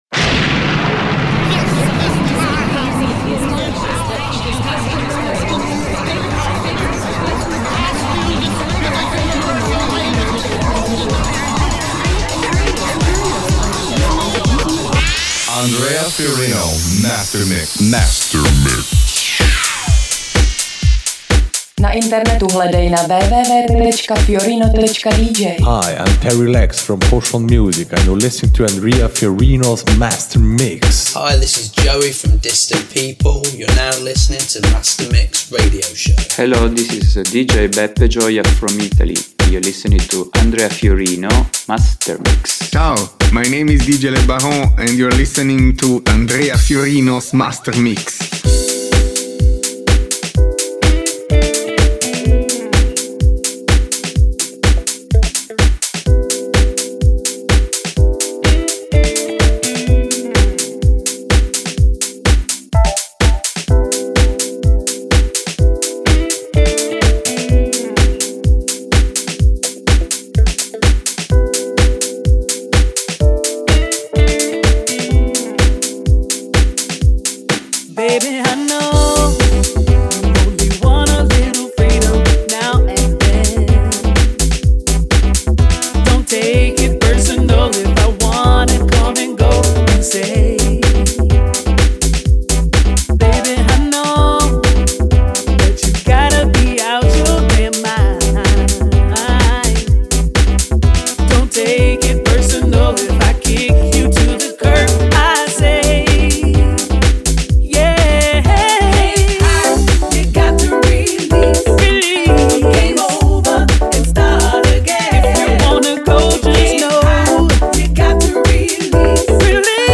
Styl: house